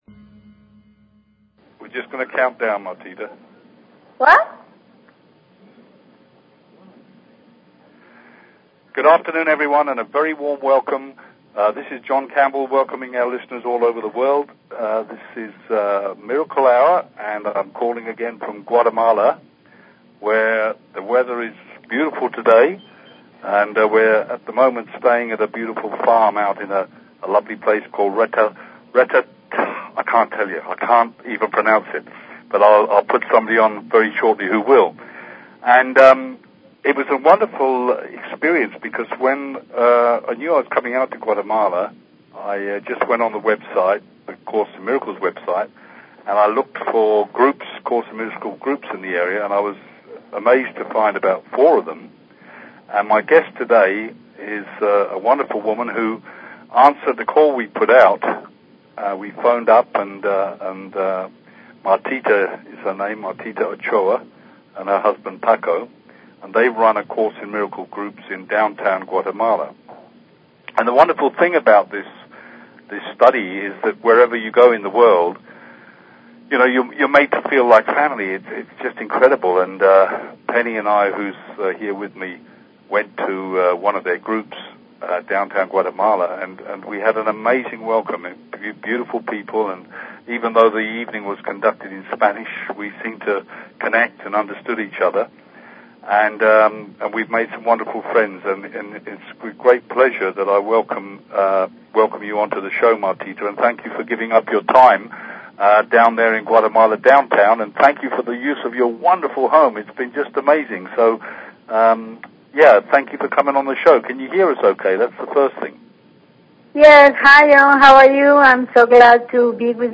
Talk Show Episode, Audio Podcast, Miracle_Hour and Courtesy of BBS Radio on , show guests , about , categorized as